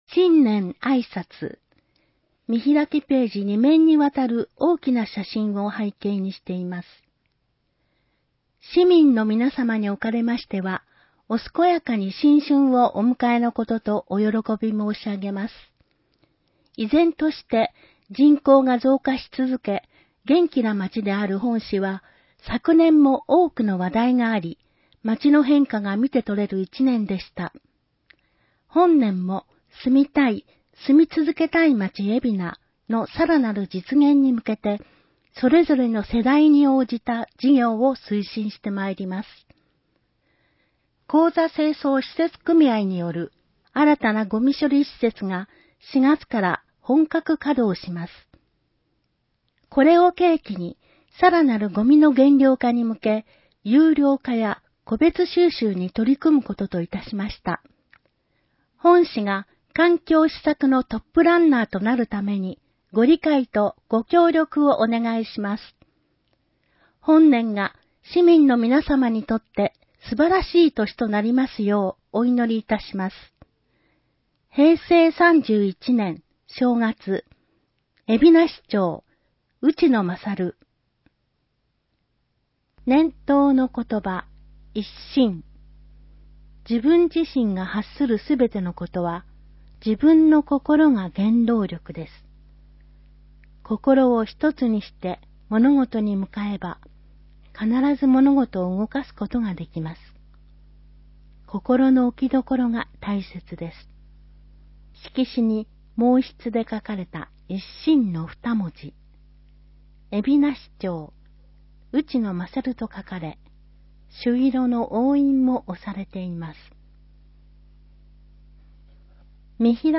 広報えびな 平成31年1月1日号（電子ブック） （外部リンク） PDF・音声版 ※音声版は、音声訳ボランティア「矢ぐるまの会」の協力により、同会が視覚障がい者の方のために作成したものを登載しています。